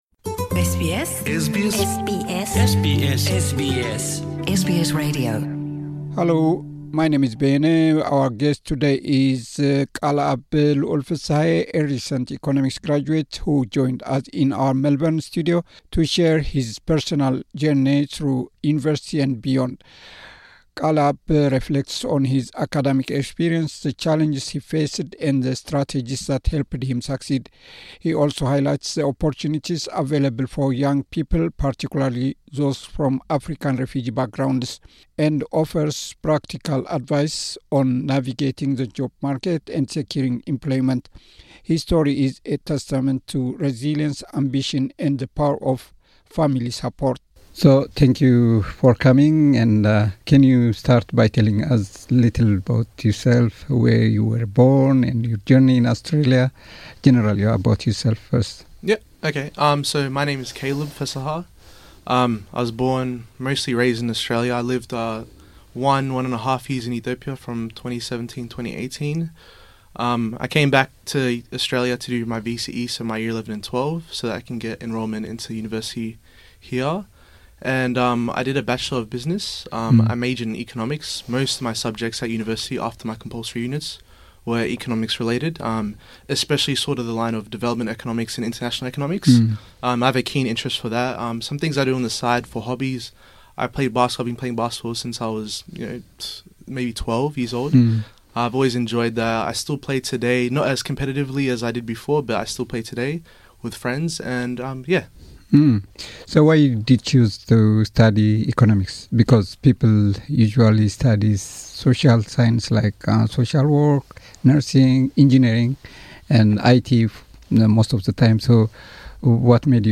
Interview
visited our Melbourne studio